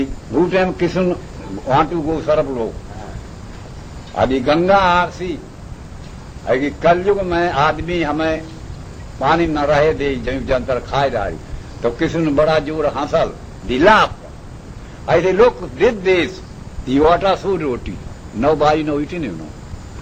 The informants spoke in a mixture of Hindi and English. They dealt generally with life in India in the early 20th century; the experience of the immigrants - the journey, life on board ship; their duties as workers in cane on the estates; the practice of religion during the indentureship period.
9 audio cassettes